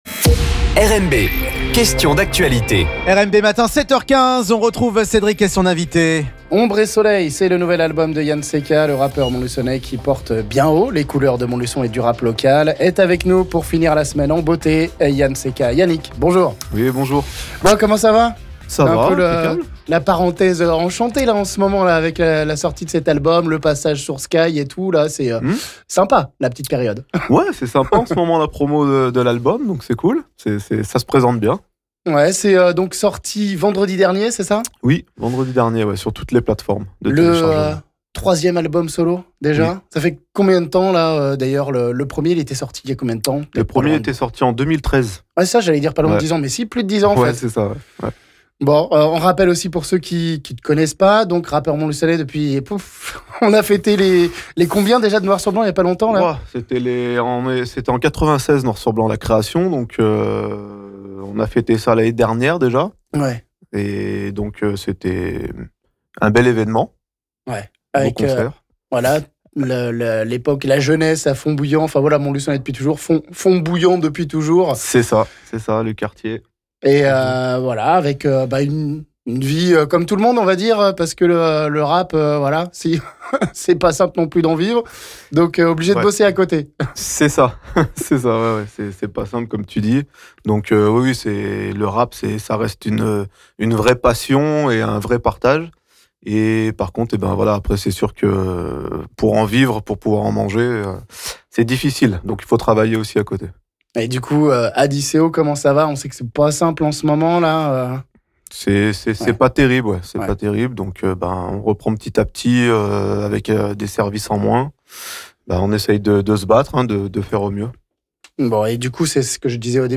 Il nous apporte du soleil et de l'amour, sur fon de sons ragga, pop, rap et reggae